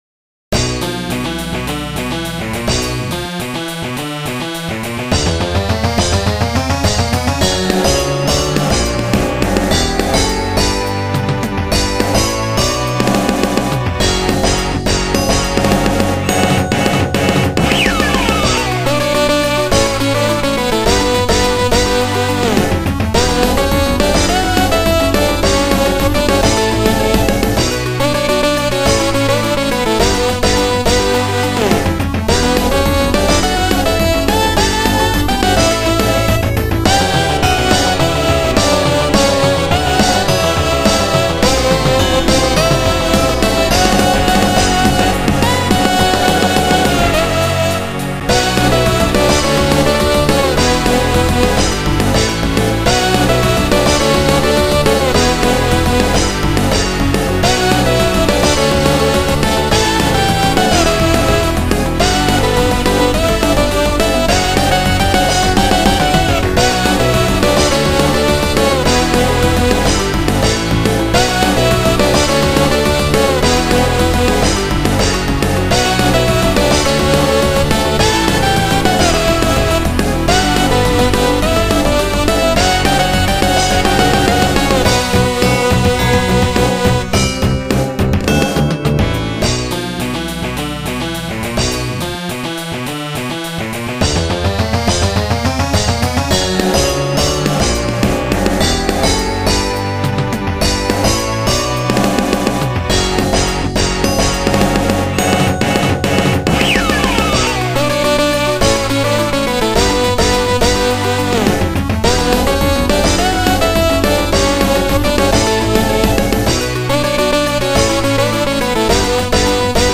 という訳でノリノリな劇中挿入歌です。
つーか着メロでギターは鬼門だッ。どうしてもハイがぬるくなるし、FM音色でやると軽いし！